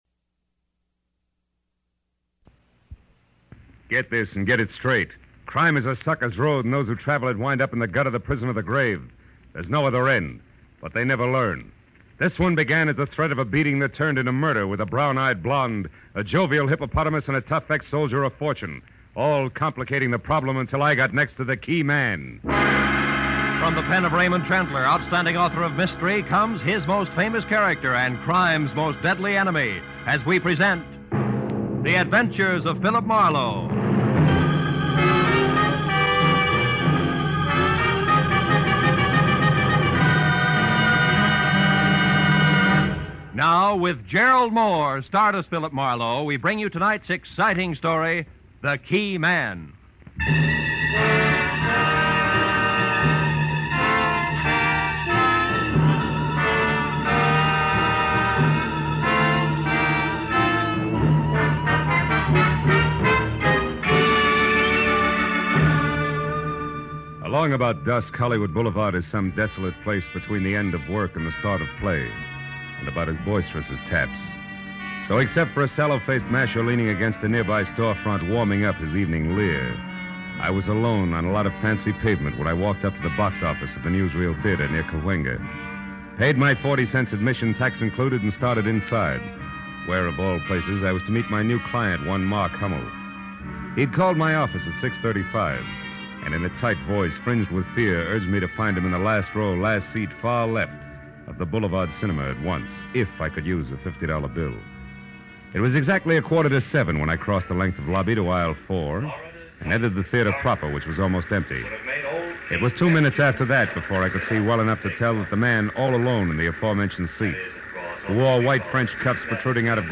The program first aired 17 June 1947 on NBC radio under the title The New Adventures of Philip Marlowe, with Van Heflin playing Marlowe.
In 1948, the series moved to CBS, where it was called The Adventure of Philip Marlowe, with Gerald Mohr playing Marlowe.